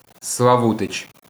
«Славу́тич» (укр. «Славу́тич»,  (Звук